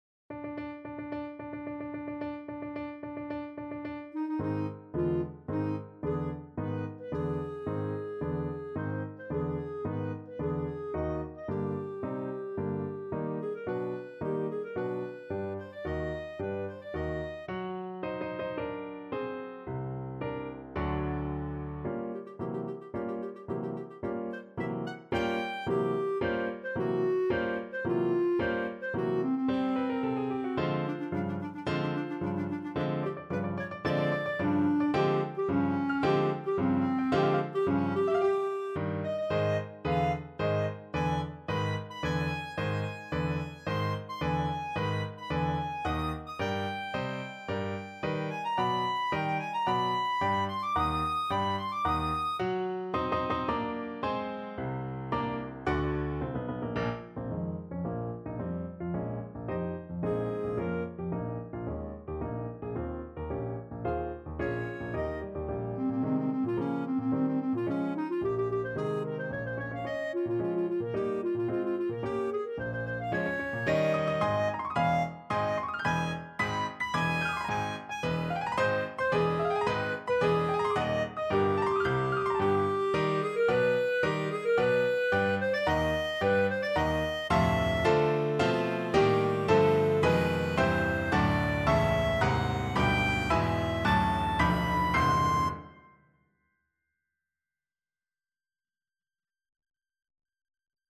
4/4 (View more 4/4 Music)
~ = 110 Tempo di Marcia
Classical (View more Classical Clarinet Music)